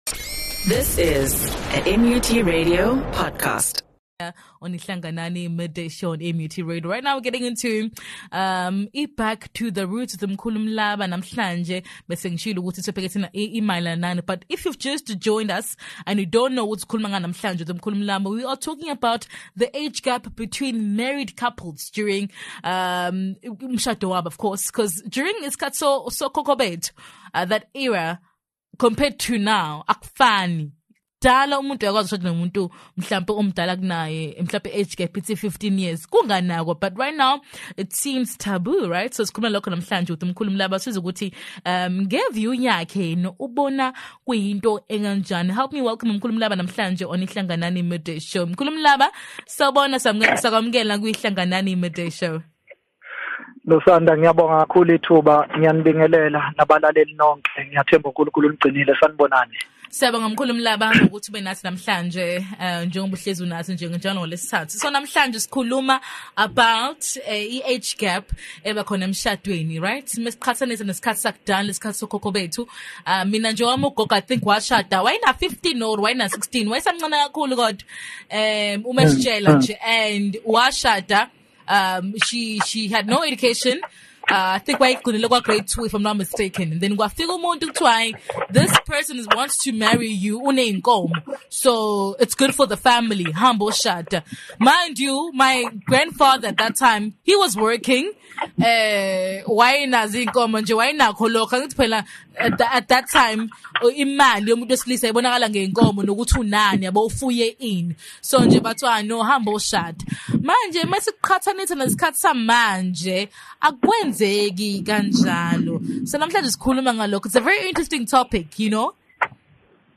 had an interview